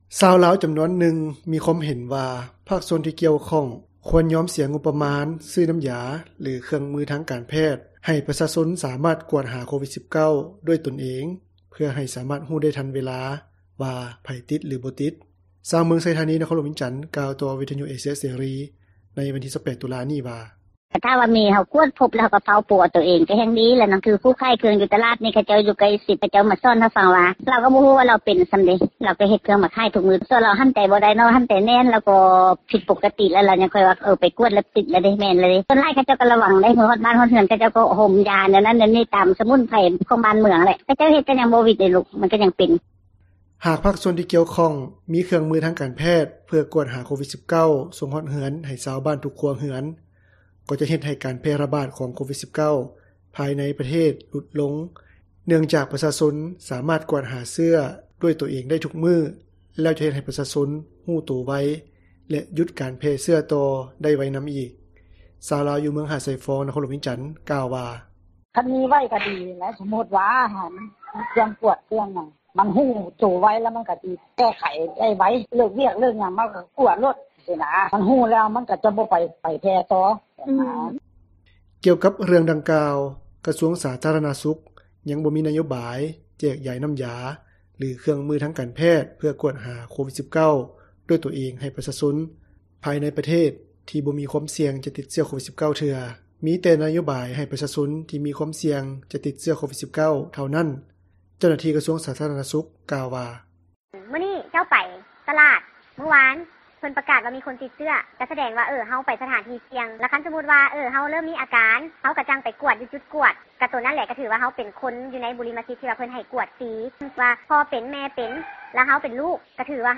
ຊາວລາວ ຈຳນວນນຶ່ງ ມີຄວາມເຫັນວ່າ ພາກສ່ວນທີ່ກ່ຽວຂ້ອງ ຄວນຍອມເສັຽງົບປະມານ ຊື້ນ້ຳຢາ ຫຼືເຄື່ອງມືທາງການແພດ ໃຫ້ປະຊາຊົນ ສາມາດກວດຫາໂຄວິດ-19 ດ້ວຍຕົນເອງ ເພື່ອໃຫ້ສາມາດຮູ້ ໄດ້ທັນເວລາວ່າ ໃຜຕິດ ຫຼືບໍ່ຕິດ. ຊາວເມືອງໄຊທານີ ນະຄອນຫຼວງວຽງຈັນ ກ່າວຕໍ່ວິທຍຸເອເຊັຽເສຣີ ໃນວັນທີ 28 ຕຸລາ ນີ້ວ່າ:
ຊາວລາວ ຢູ່ເມືອງຫາດຊາຍຟອງ ນະຄອນຫຼວງວຽງຈັນ ກ່່າວວ່າ: